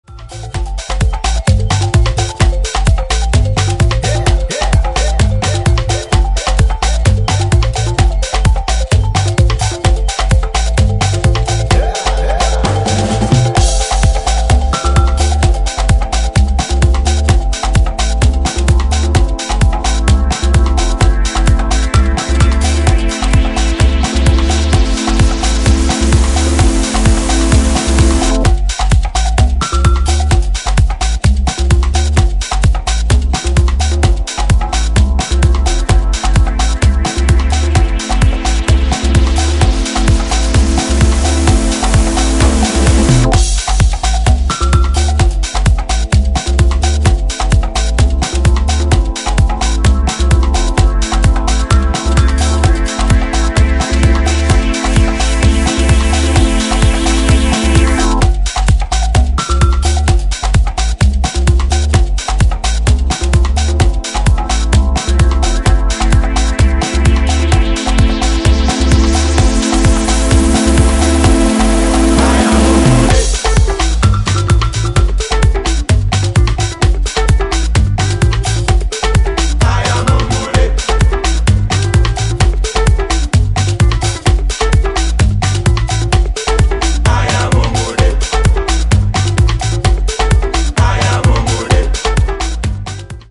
再入荷【12"INCH】(レコード)
ジャンル(スタイル) HOUSE / AFRO